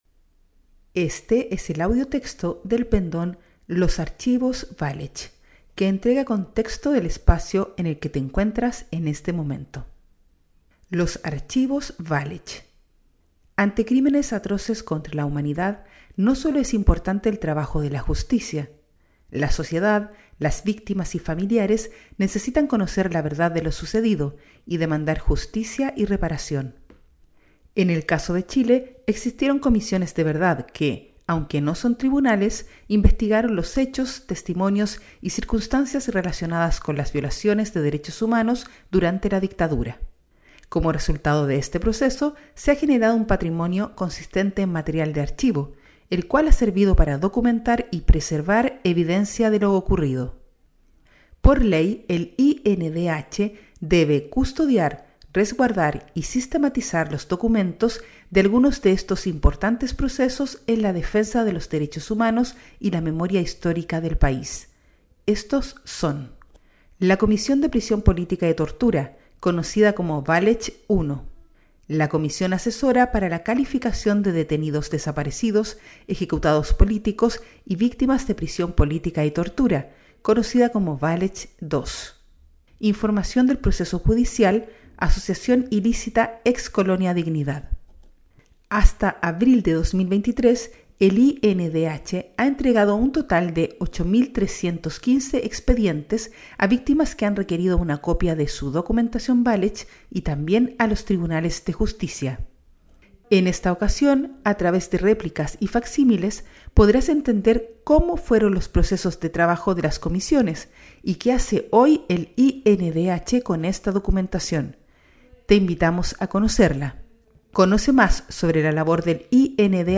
Audiotexto
AUDIOTEXTO-ARCHIVOS-VALECH.mp3